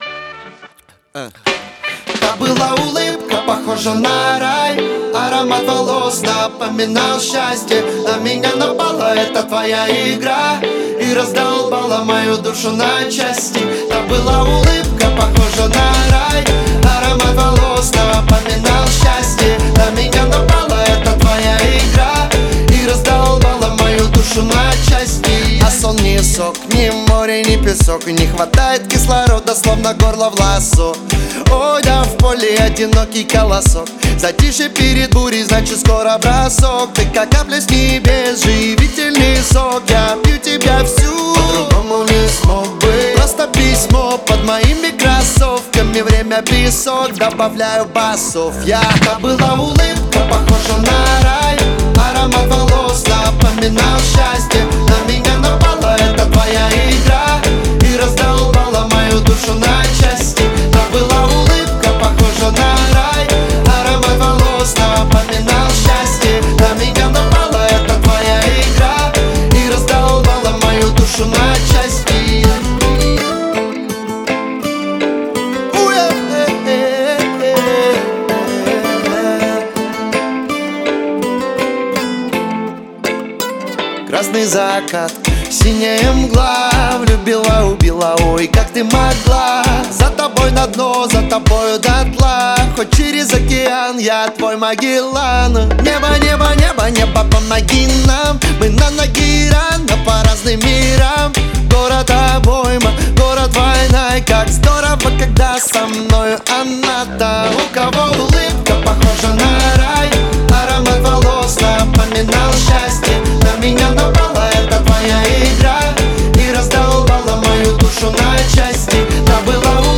• Категории: Музыка 2020, Поп